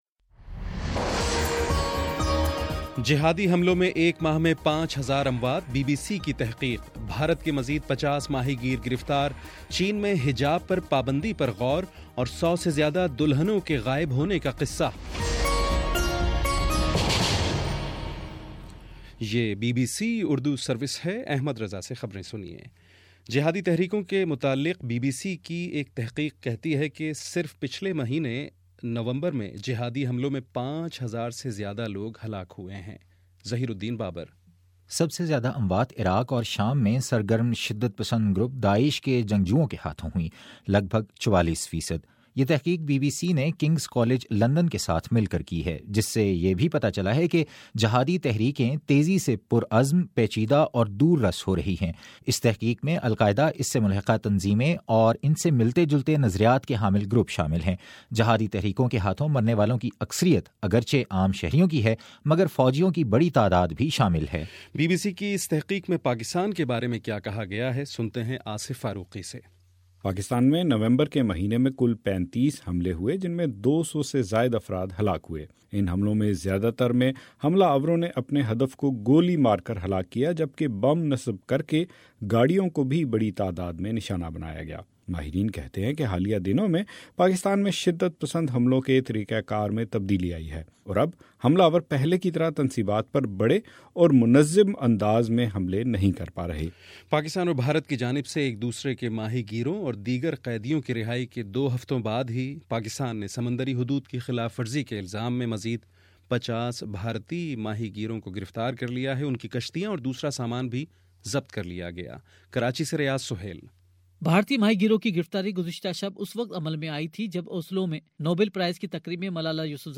دسمبر 11 : شام چھ بجے کا نیوز بُلیٹن